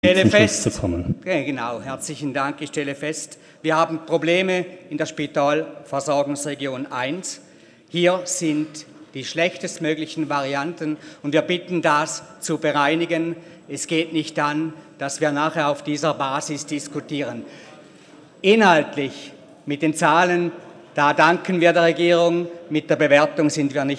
25.11.2019Wortmeldung
Sprecher: Hartmann-Flawil
Session des Kantonsrates vom 25. bis 27. November 2019